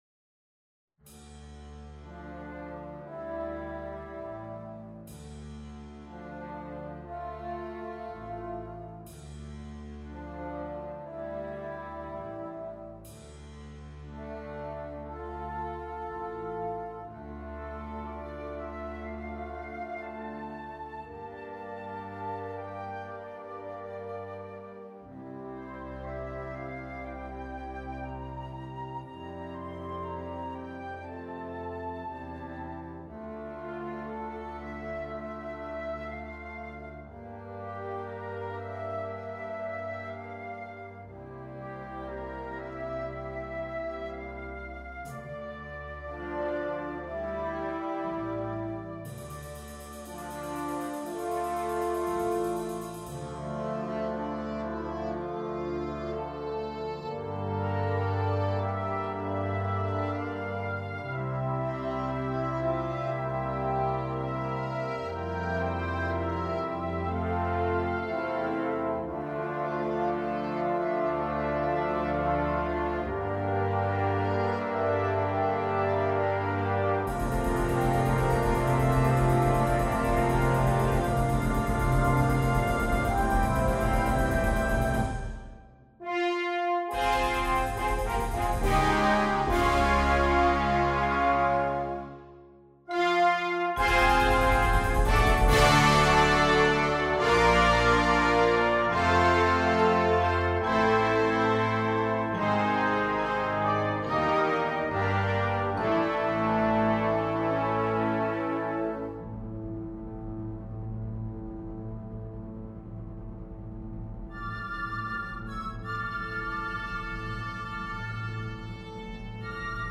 Gattung: Konzertwerk
Besetzung: Blasorchester
während das Werk zu seinem triumphalen Abschluss kommt.